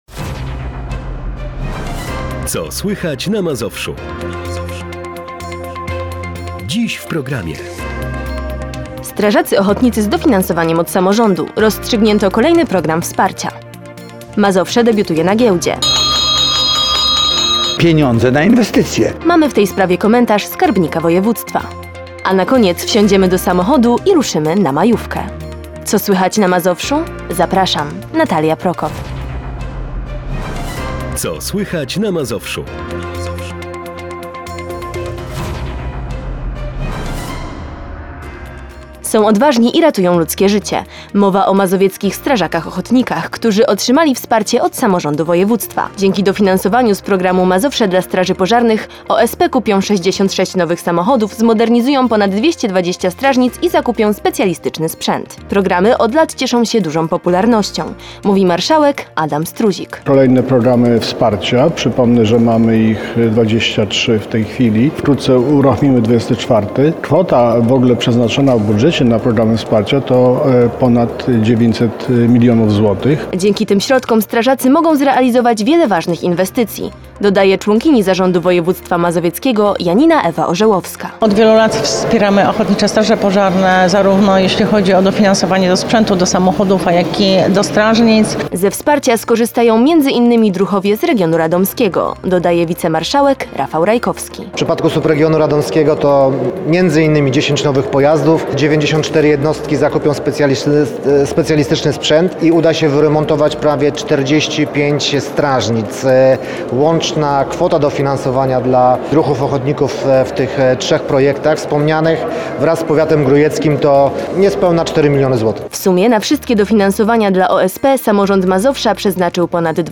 FILM Z NASZEGO STUDIA PLENEROWEGO - Radio Bogoria
Nasze studio plenerowe towarzyszy wielu imprezom Zachodniego Mazowsza.
Pierwszy raz nadawaliśmy na żywo równolegle w eterze na 94,5 Fm i filmowo na kanale You Tube.